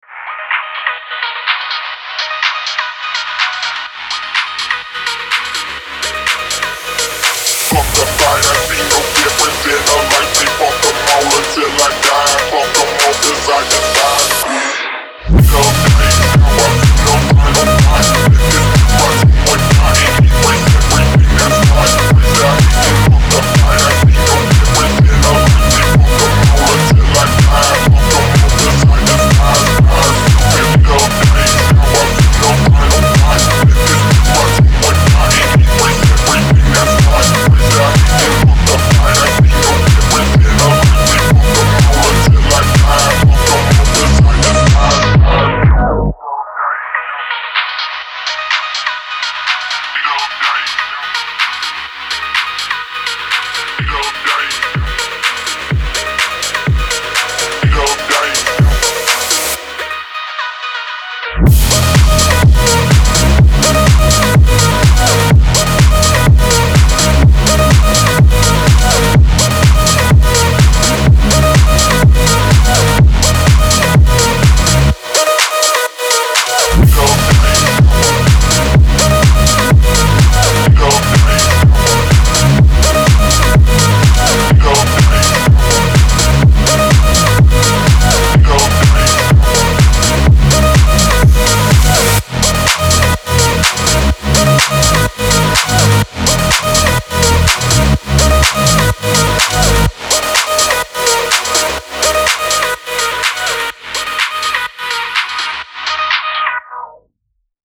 Трек размещён в разделе Зарубежная музыка / Фонк.